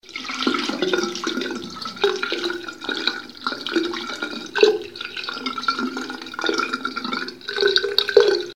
Agua introduciéndose por el desagüe del lavabo
Grabación sonora del sonido de agua corriendo introduciéndose por el desagüe del lavabo.
Sonidos: Agua